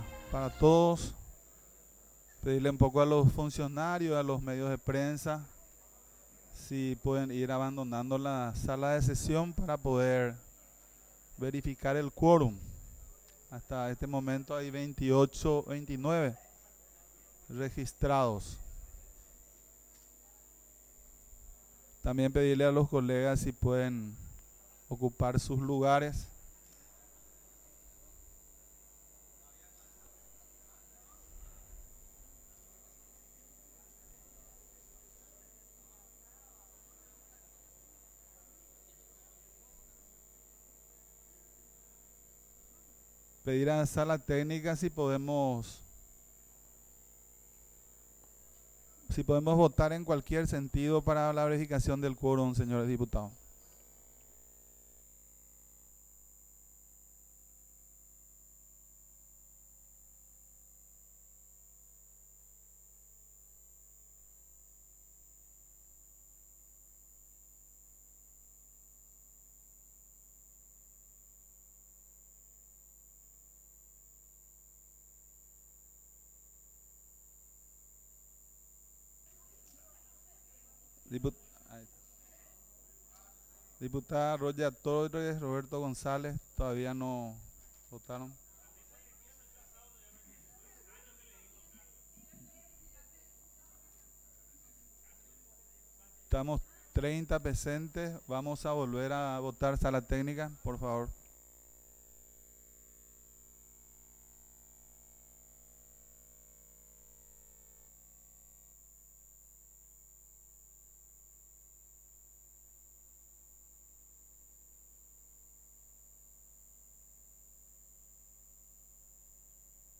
Sesión Ordinaria, 21 de junio de 2023